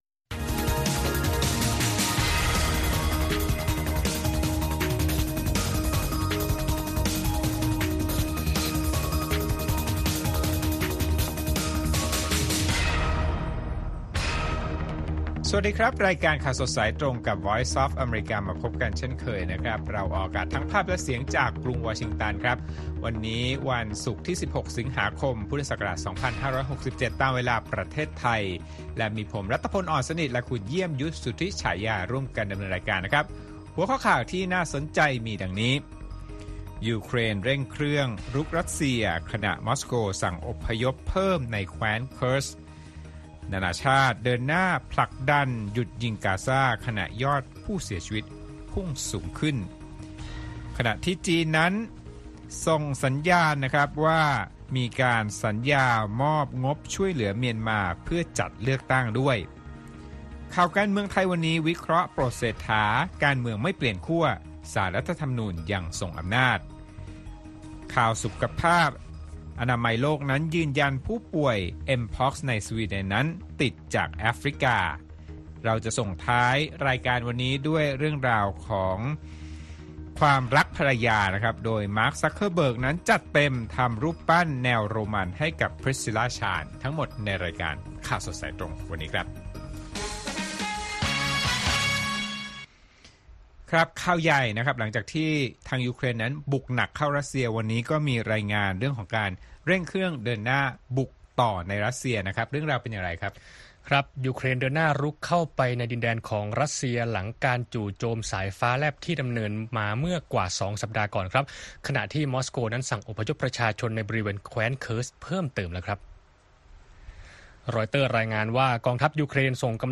ข่าวสดสายตรงจากวีโอเอไทย วันศุกร์ ที่ 16 ส.ค. 2567